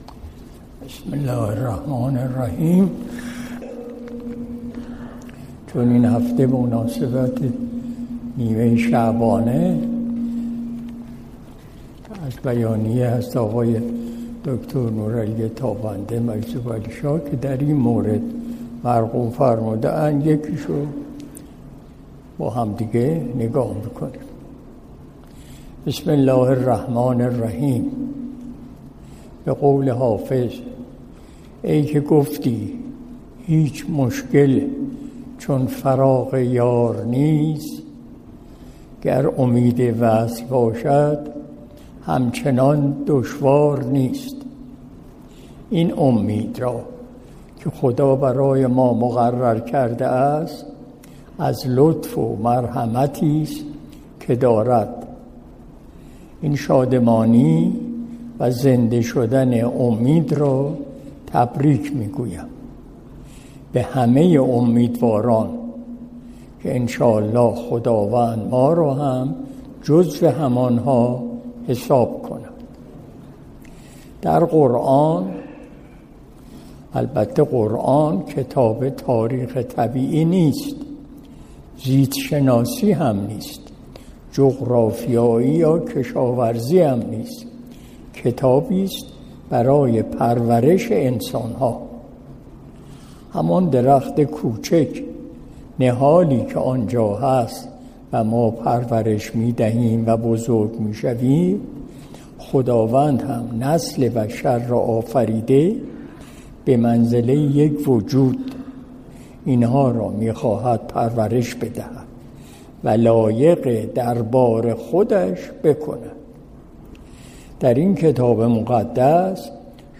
مجلس شب دوشنبه ۱۴ اسفند ماه ۱۴۰۱ شمسی